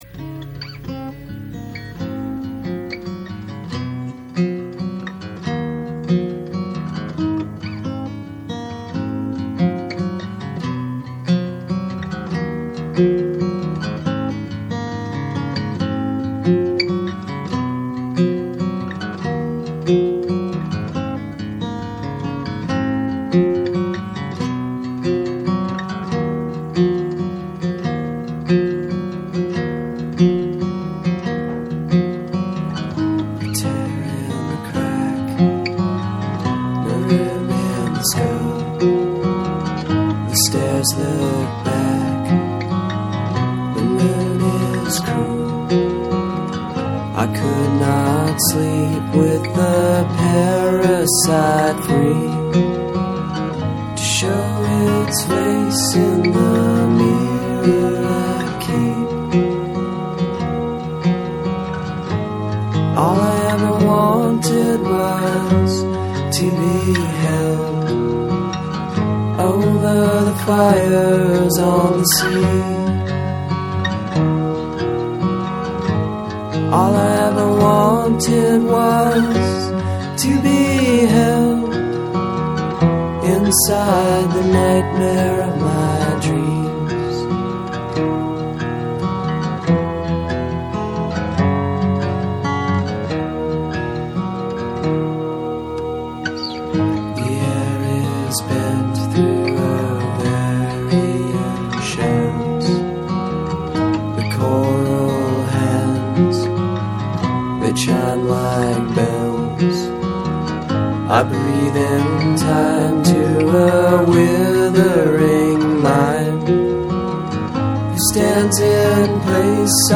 Again with the blip at the start of the track.